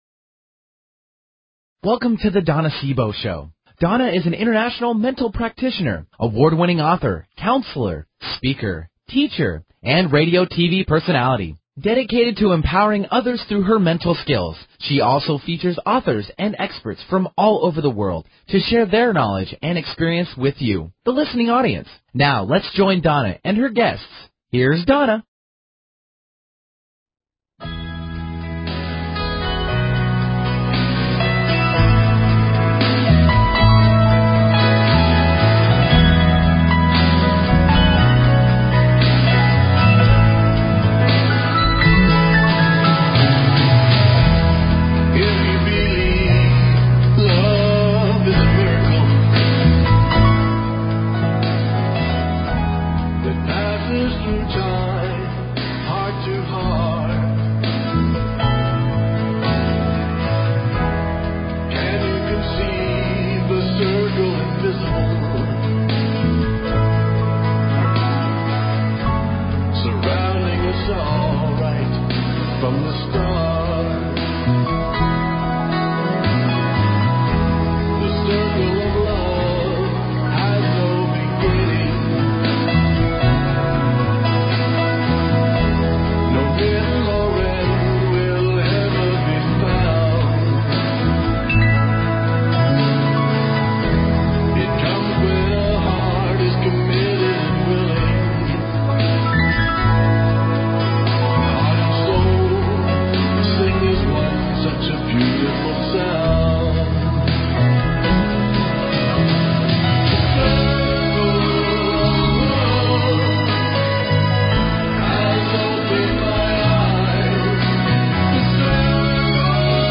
Talk Show Episode, Audio Podcast
The music of love and its lyrics will fill the airwaves and I know it will touch your heart and spirit.